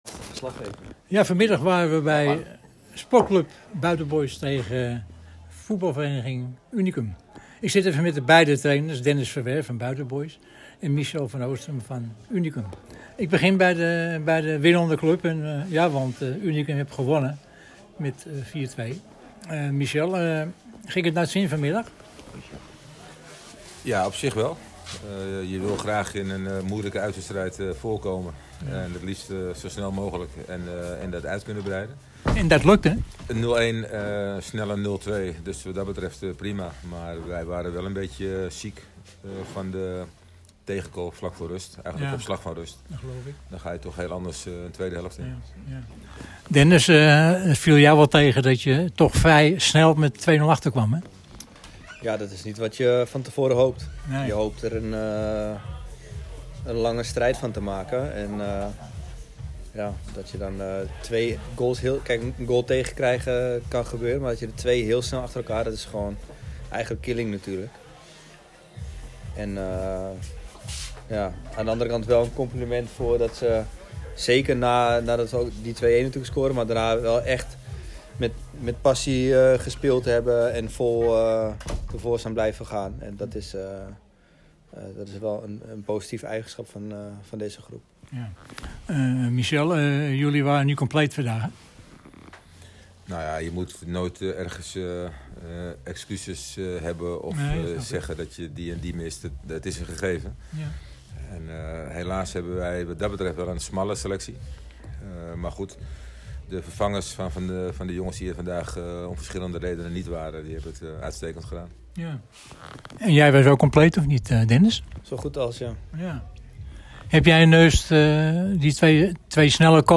Hieronder interview